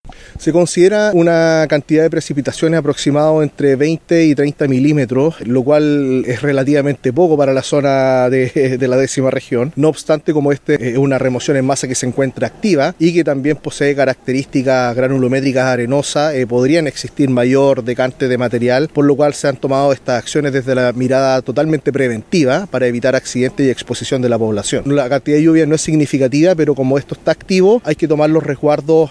En tanto, el director regional de Senapred, Mitzio Riquelme, abordó las medidas desde el punto de vista preventivo, ya que las precipitaciones anunciadas no serán de grandes cantidades.